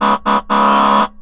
Tesla rolls out futuristic horn sound for all Cybertruck owners
Tesla has given Cybertruck owners the option to add a new futuristic horn sound.
Cybertruck horn sound here.